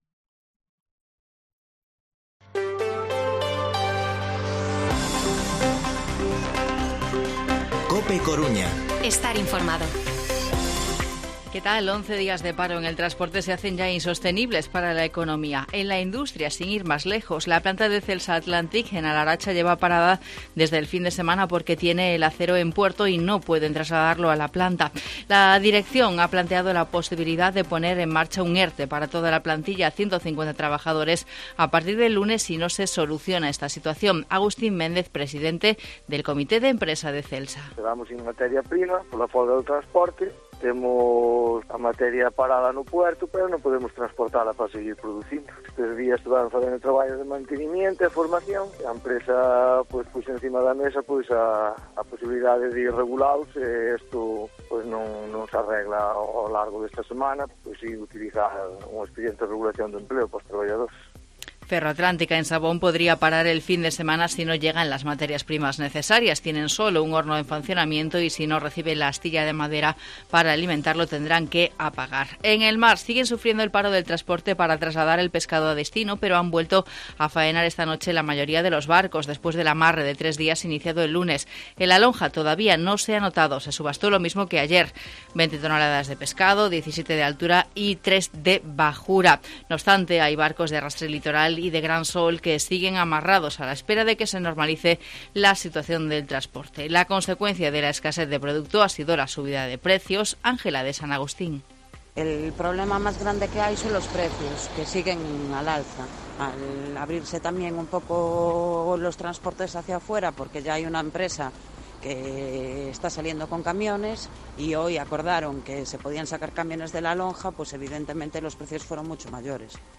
Informativo Mediodía COPE Coruña jueves, 24 de marzo de 2022 14:20-14:30